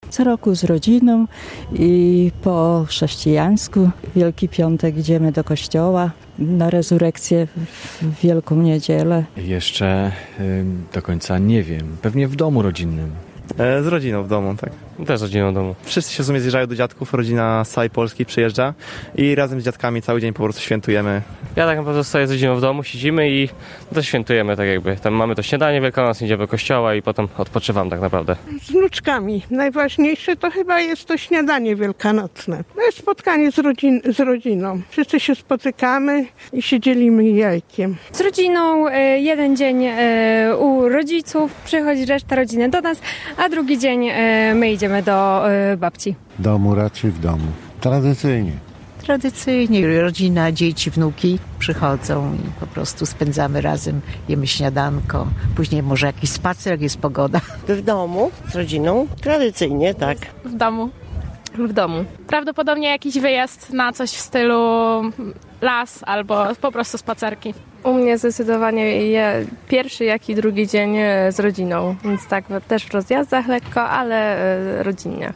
Jak i gdzie spędzamy Wielkanoc? (sonda)
Większość osób spędza je w rodzinnym gronie. Zapytaliśmy mieszkańców Rzeszowa, w jaki sposób postanowili w tym roku obchodzić Wielkanoc?